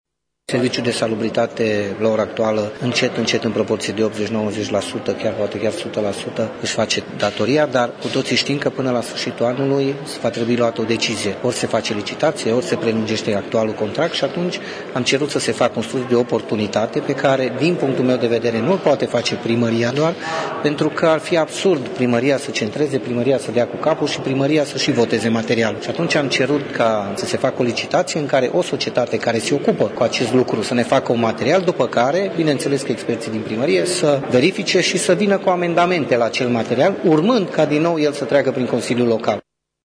Pentru a găsi cea mai bună soluţie, consilierii locali au aprobat, ieri, o hotărâre pentru realizarea unui studiu de oportunitate, a explicat viceprimarul Claudiu Maior: